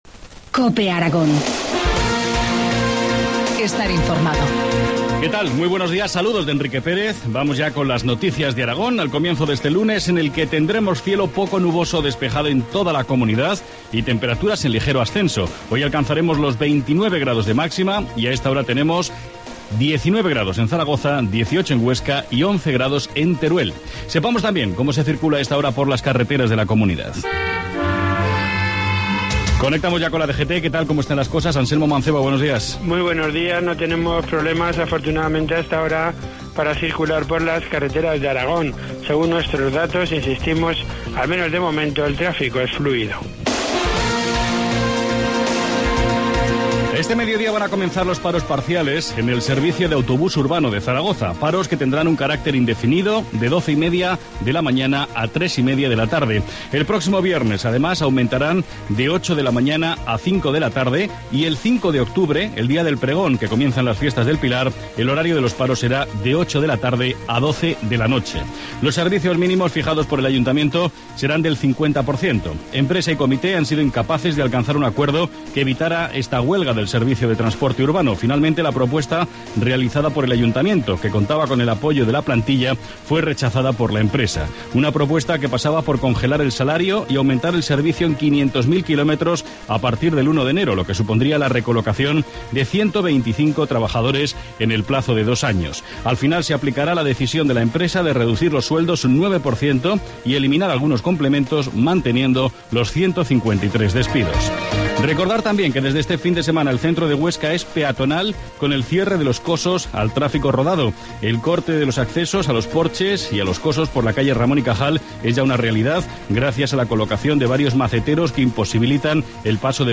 Informativo matinal, lunes 23 septiembre, 2013, 7,25 horas